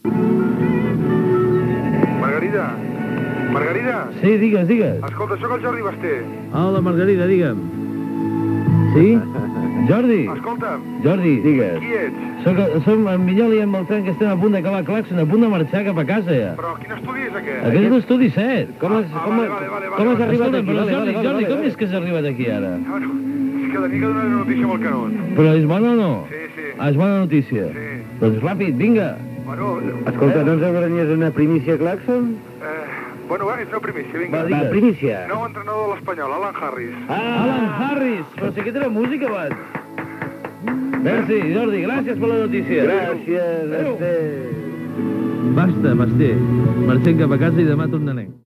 Musical
Per equivocació, des del control central de l'emissora envien la seva trucada a l’estudi 7 on en aquells moments s’estava radiant en directe per Ràdio Associació RAC 105 el programa Claxon.